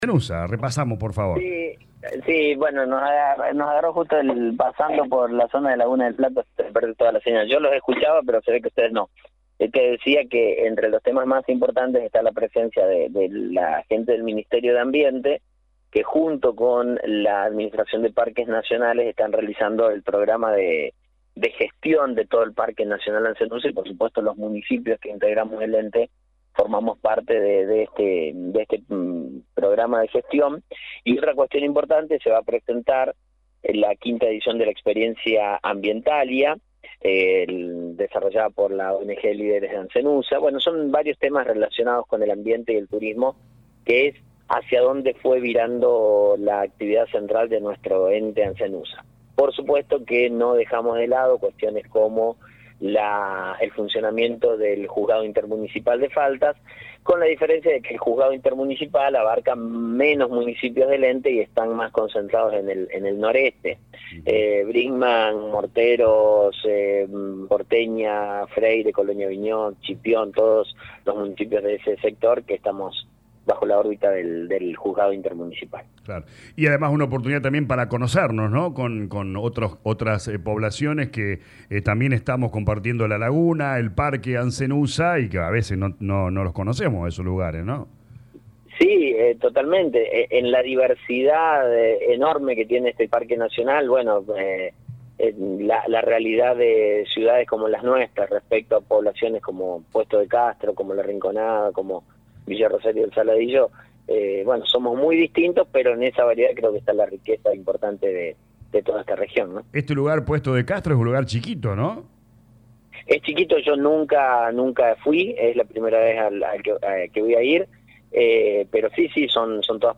Este martes se realiza una importante reunión del Ente Ansenuza en Puesto de Castro, un encuentro que abordará temas clave relacionados con el medio ambiente y el turismo. Así lo confirmó Mauricio Actis, Intendente de Brinkmann, durante una entrevista en LA RADIO 102.9. Este evento reunirá a diversas autoridades del ENTE y representantes de instituciones del ámbito ambiental.